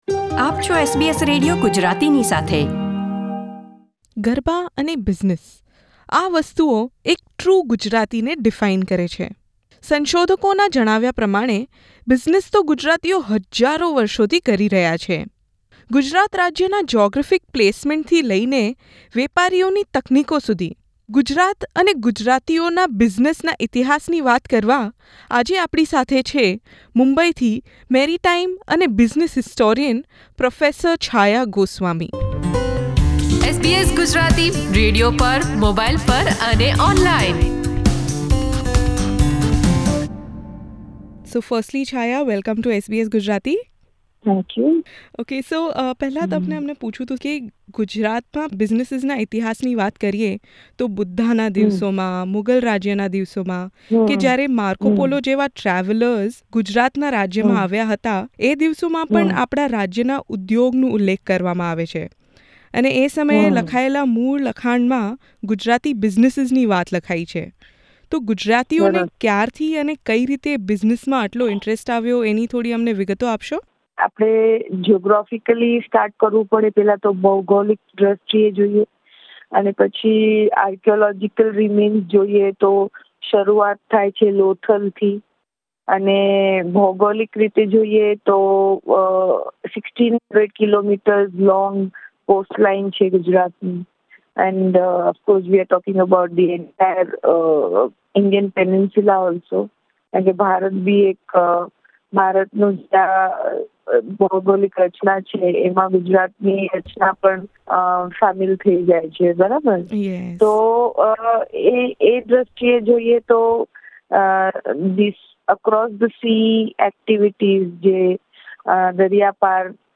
Why do historical accounts of Greek, Roman and Arab travellers mention and praise Gujarati merchants? SBS Gujarati tries to get to the bottom of these questions in an interview with historian